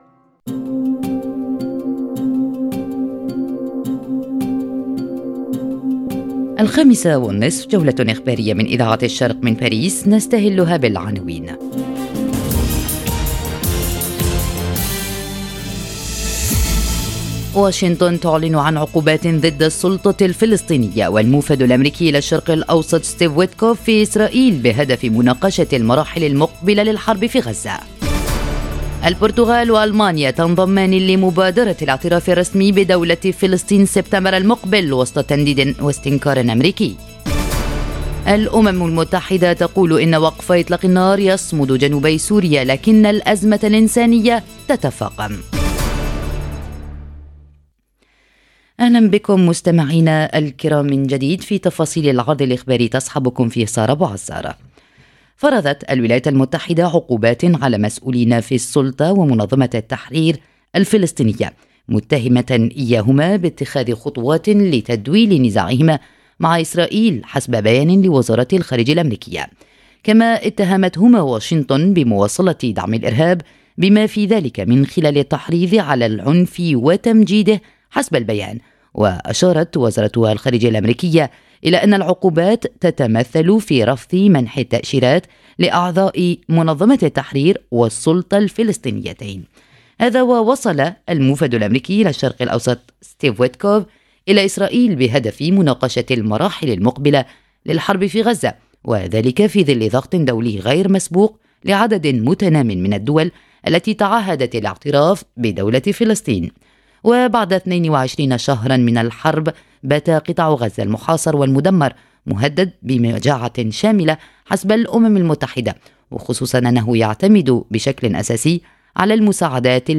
نشرة أخبار المساء: عقوبات من واشنطن على السلطة الفلسطنية، والمانيا والبرتغال تنضمان لمبادرة الاعتراف بدولة فلسطين - Radio ORIENT، إذاعة الشرق من باريس